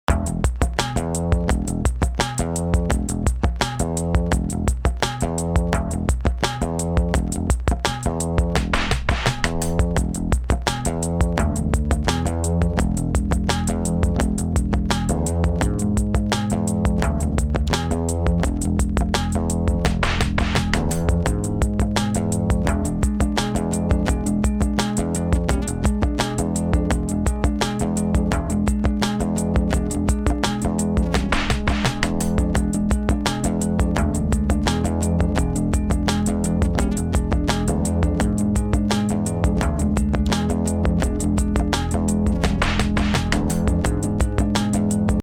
home of the daily improvised booty and machines -
3 bass grooves